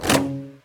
ArrowCrossBowShot-001.wav